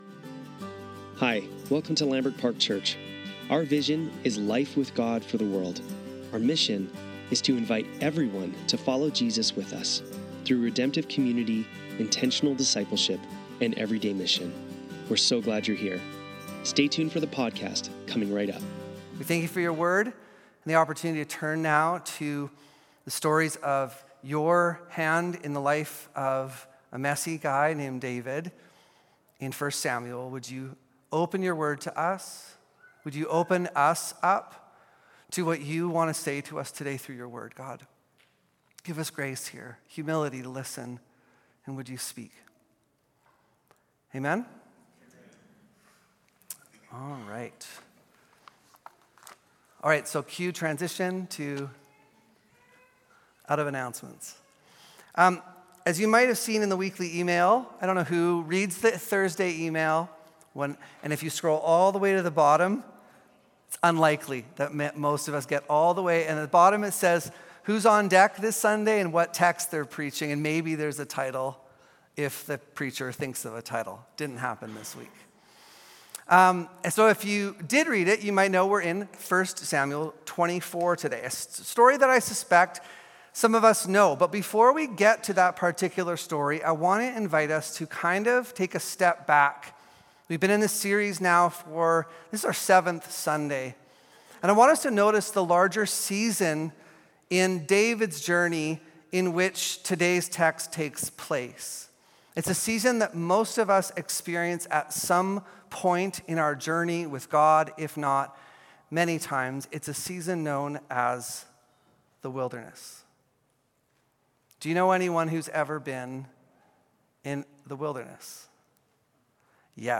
Sermons | Lambrick Park Church